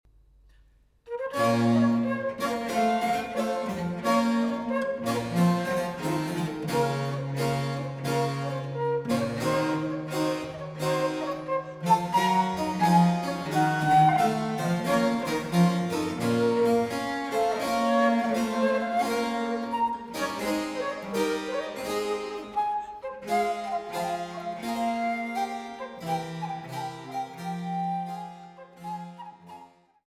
Gravement